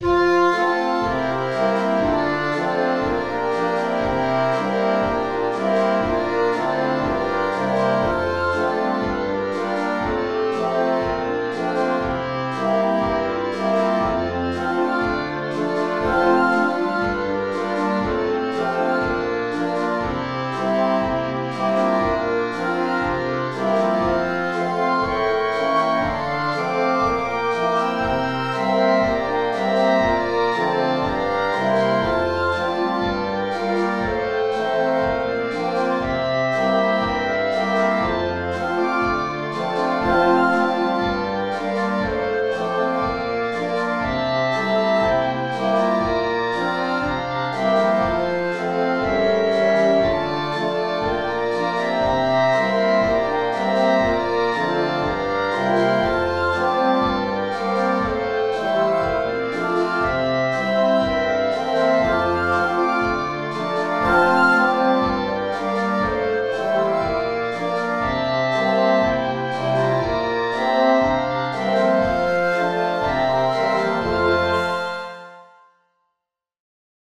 Woodwinds only: Praise His Holy Name (16 Nov) 1:17